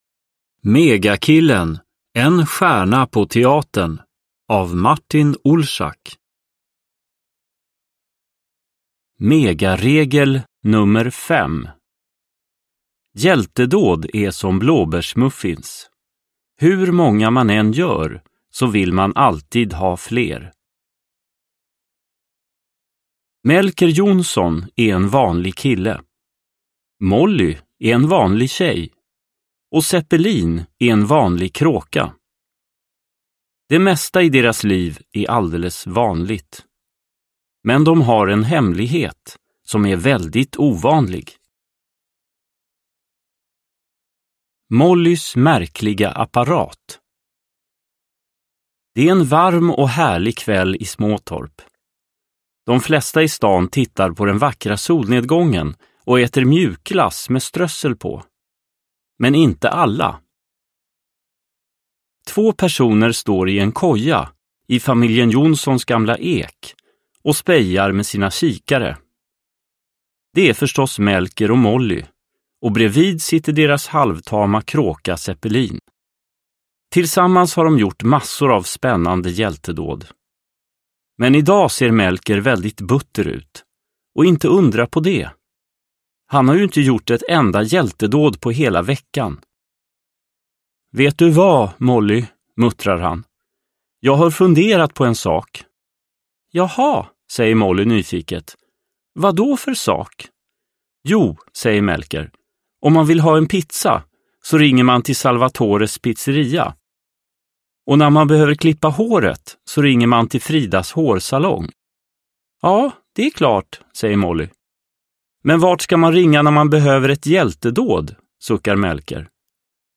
Megakillen. En stjärna på teatern – Ljudbok – Laddas ner